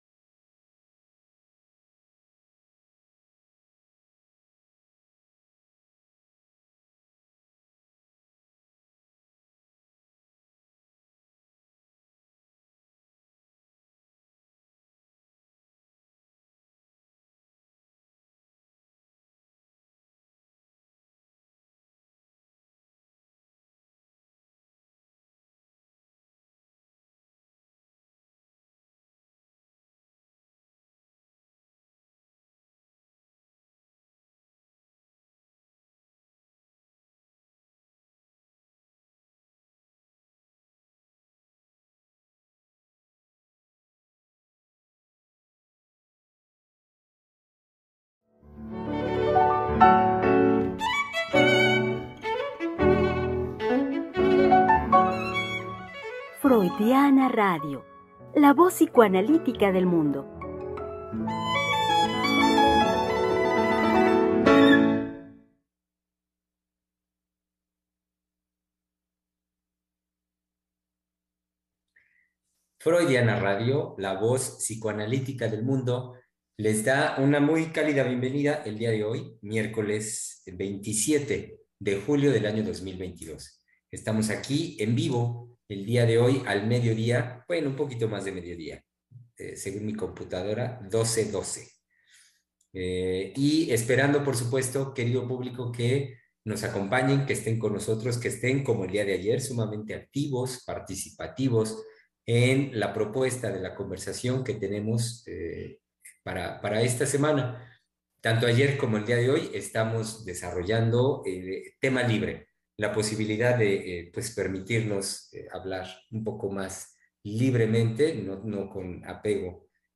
Programa transmitido el 27 de julio del 2022.